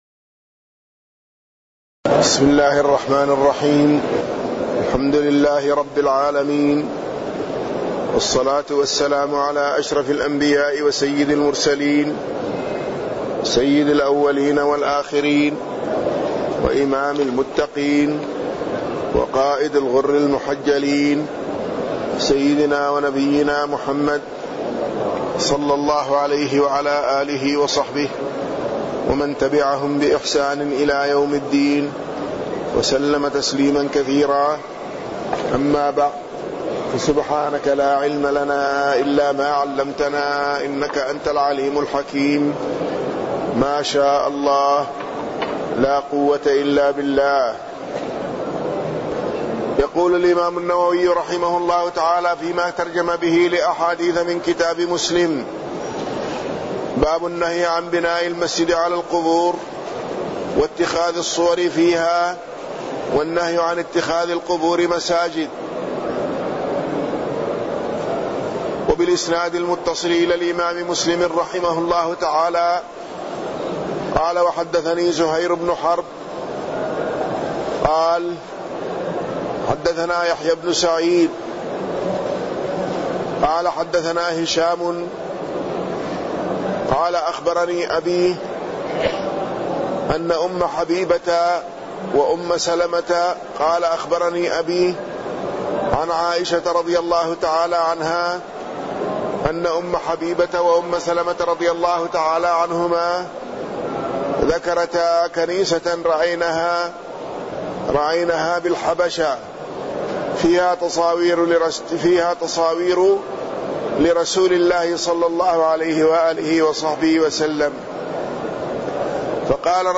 تاريخ النشر ٢٣ ربيع الثاني ١٤٢٩ هـ المكان: المسجد النبوي الشيخ